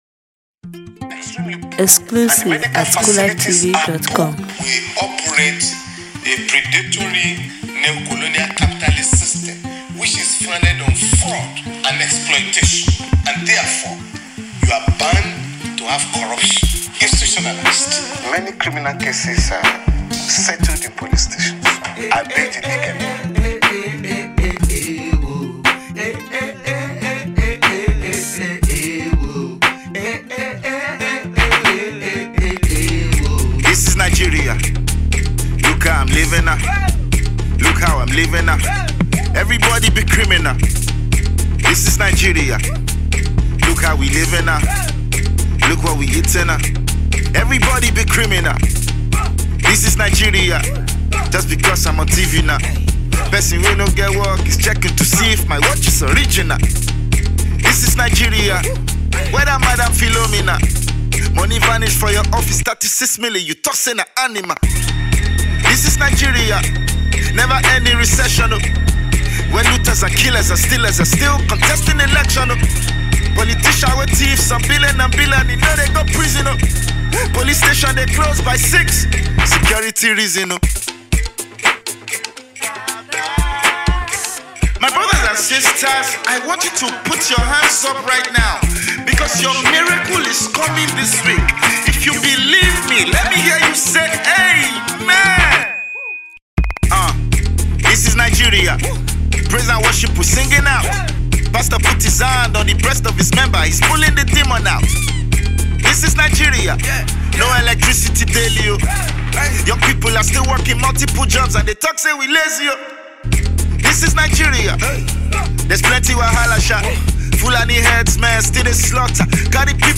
Afro Music 2018 Download